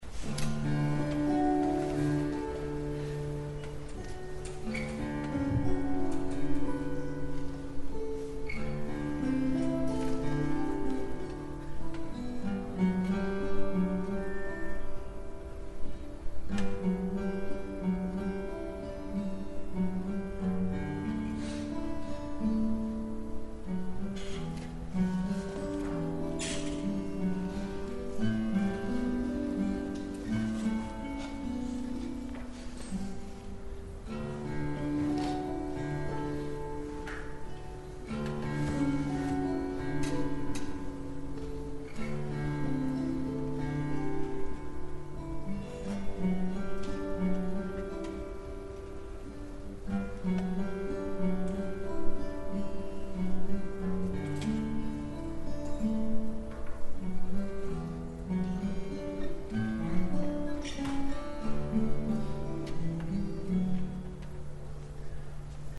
These are audio clips from the 2011 convention workshop.
Identical Lutz-topped guitars with different back and sides: